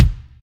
normal-hitclap.ogg